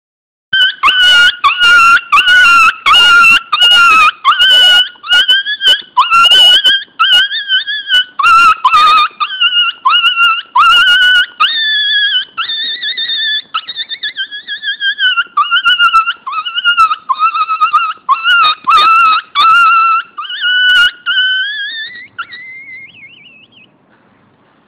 Hund (Högt) ringsignal - Djur - RingMob
Hund (Högt)